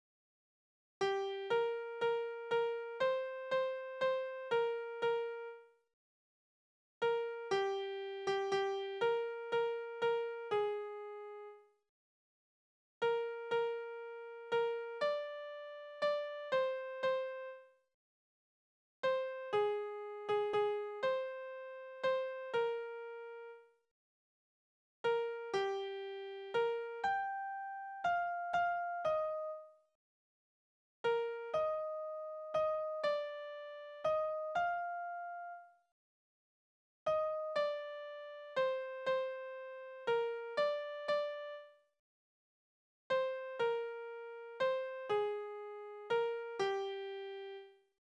Balladen: Das Kind macht dem verlassenen Mädchen neuen Lebensmut
Tonart: Es-Dur
Taktart: 3/4
Tonumfang: Oktave
Besetzung: vokal